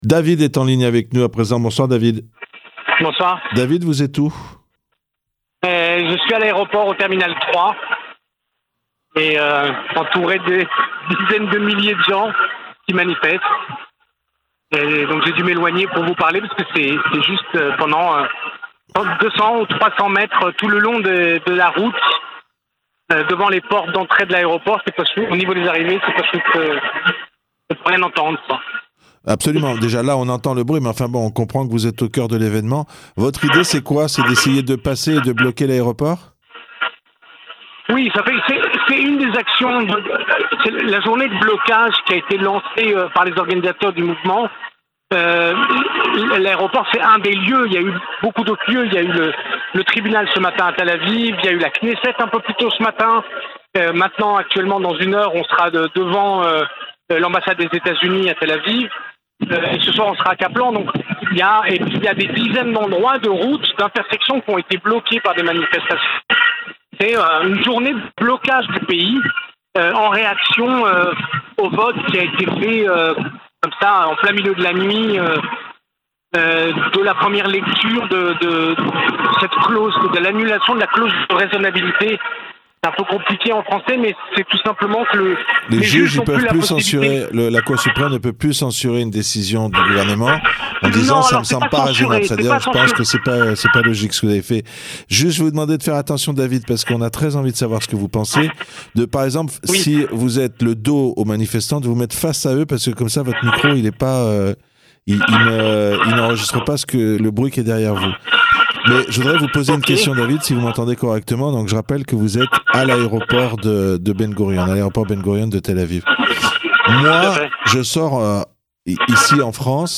Quelles sont leurs préoccupations, leurs revendications ? Témoignages de francophones recueillis en direct , sur le terrain, à l'aéroport International de Tel Aviv, à Netanyah et au coeur des Tours Azrieli de Tel Aviv